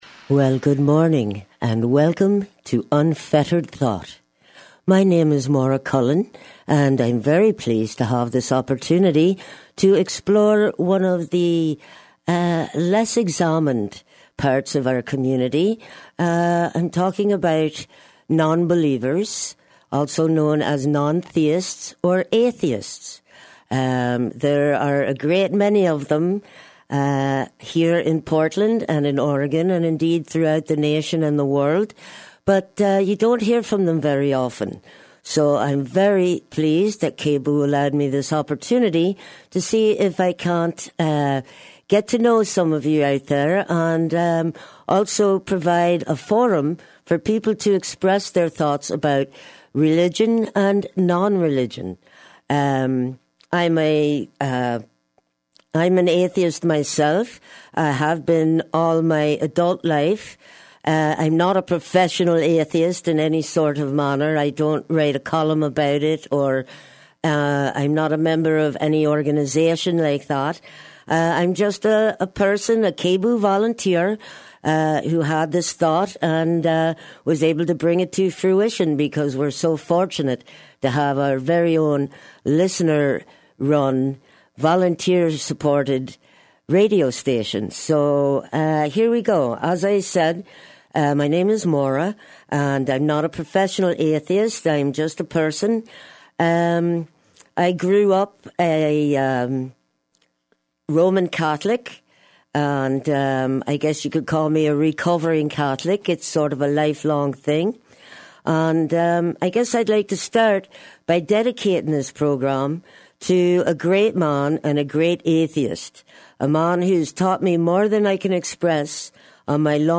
" for, by and about non-theists, but open to all. Listener calls are welcome.